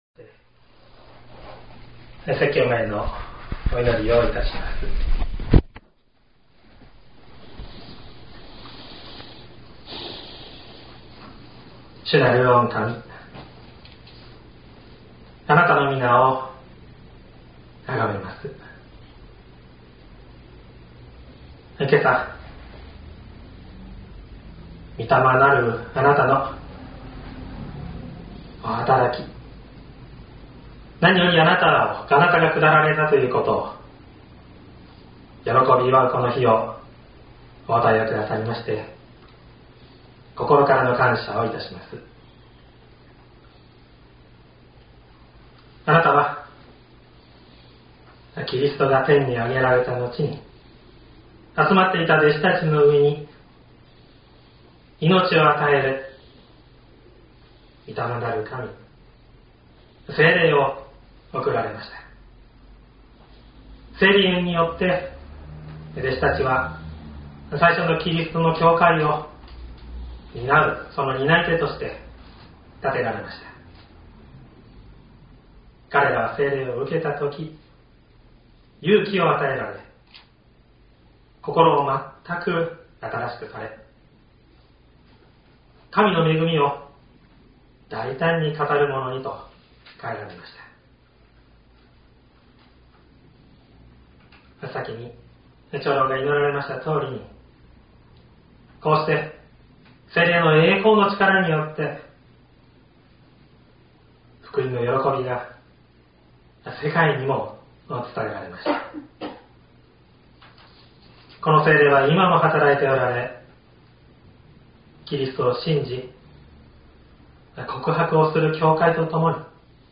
2022年06月05日朝の礼拝「苦難を受ける定め」西谷教会
音声ファイル 礼拝説教を録音した音声ファイルを公開しています。